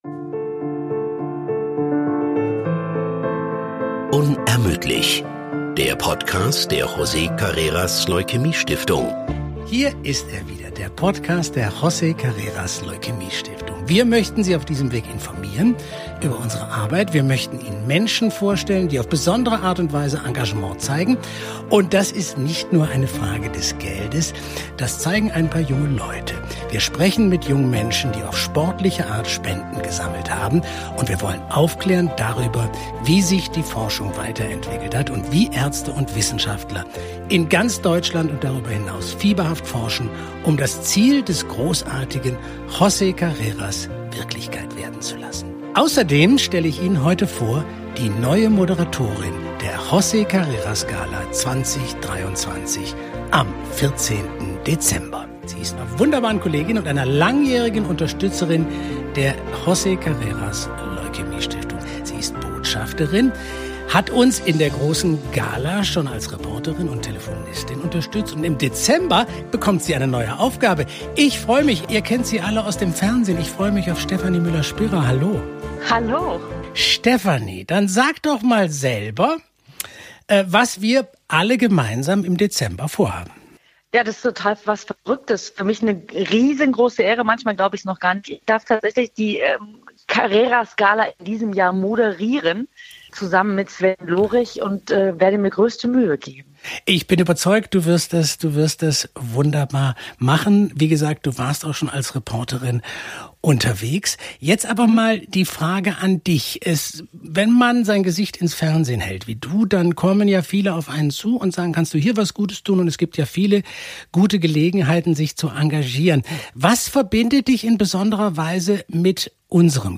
Auch in dieser Folge haben wir wieder wunderbare und optimistische Gäste eingeladen.